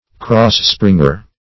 Search Result for " cross-springer" : The Collaborative International Dictionary of English v.0.48: Cross-springer \Cross"-spring`er\ (-spr?ng`?r), n. (Arch.) One of the ribs in a groined arch, springing from the corners in a diagonal direction.